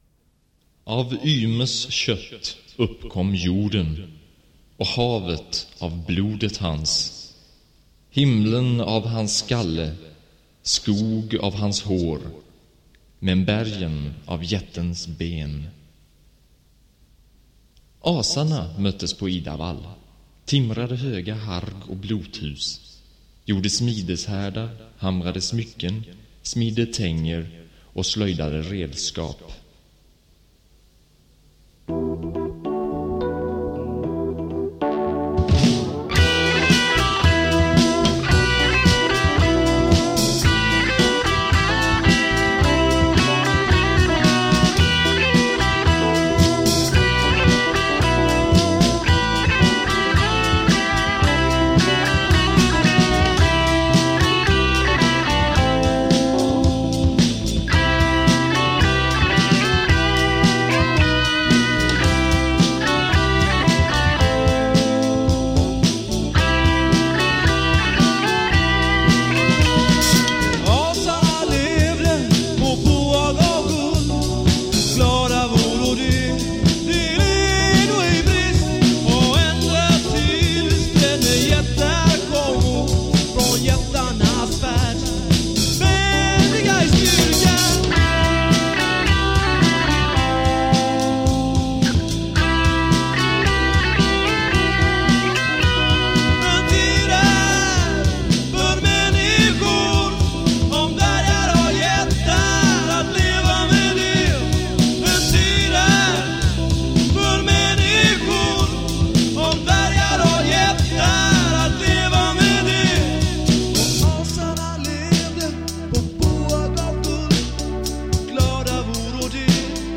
Yggdrasil var en symfonirockgrupp som fanns 1977 - 1980.
Dom här inspelningarna kommer från en TTF (timme till förfogande)
Vi fick låna PA, Hammondorgel med Leslie, Stråkmaskin och en monofon synt.
gitarr
trummor
keyboards